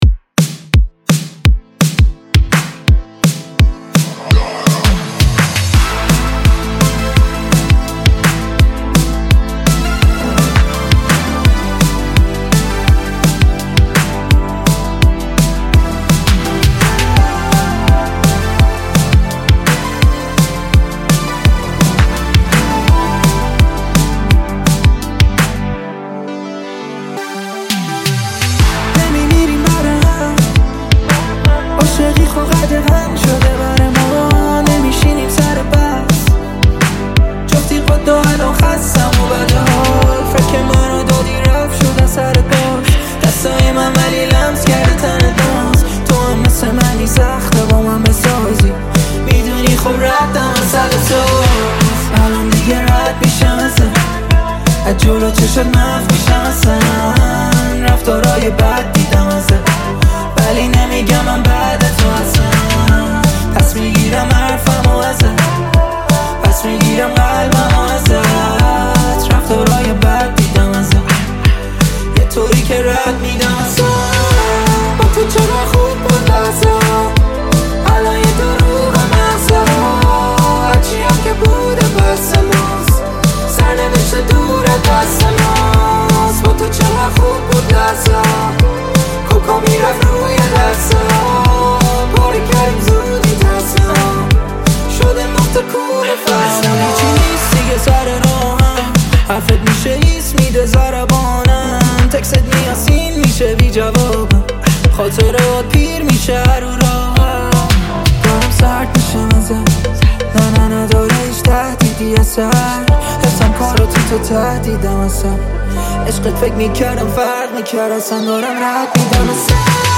رپ فارس